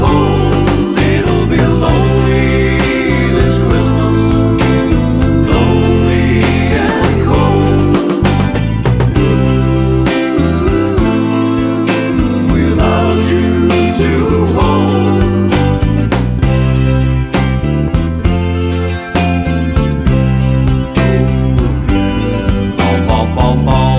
no Backing Vocals Glam Rock 3:33 Buy £1.50